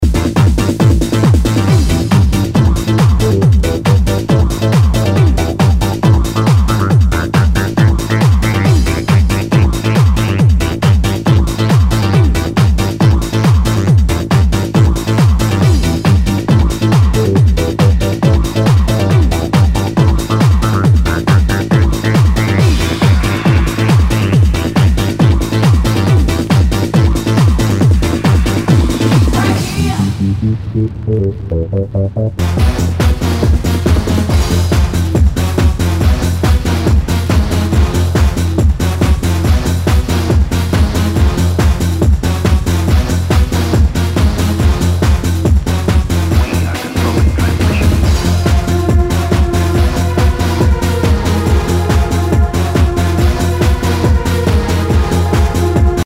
HOUSE/TECHNO/ELECTRO
ナイス！ファンキー・ハード・ハウス！
全体に大きくチリノイズが入ります。